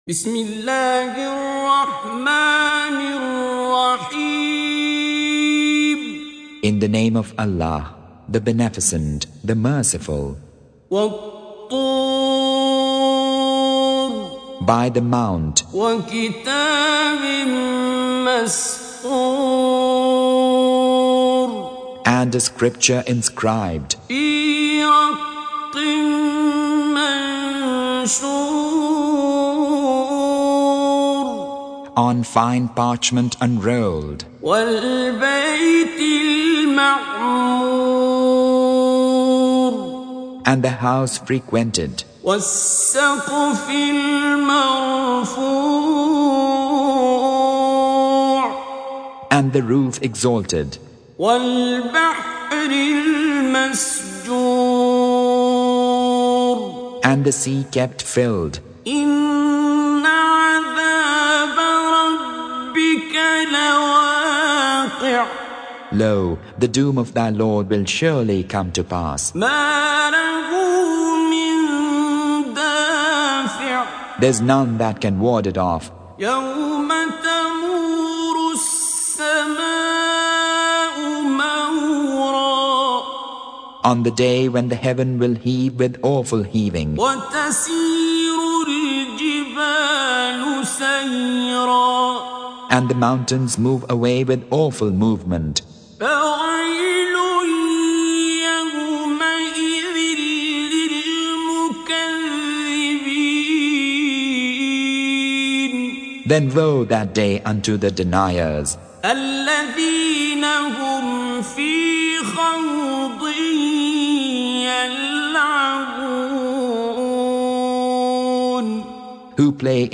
Audio Quran Tarjuman Translation Recitation Tarjumah Transliteration